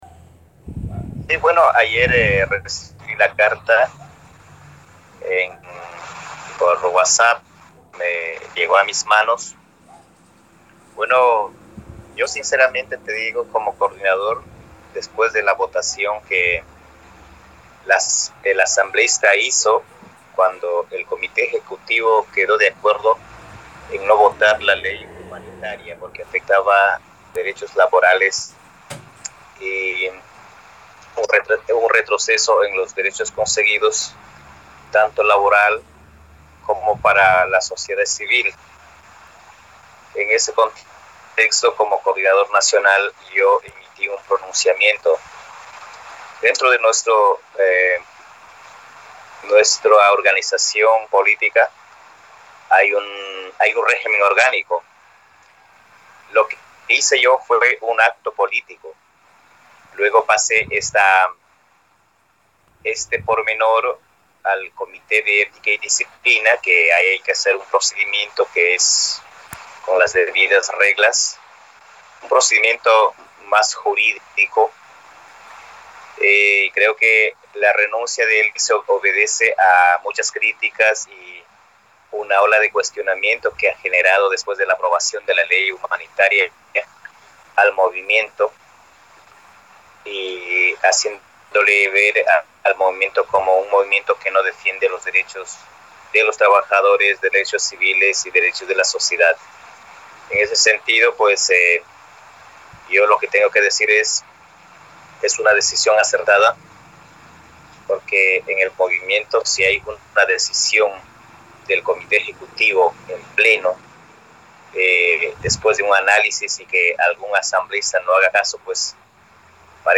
En conversación con Radio La Calle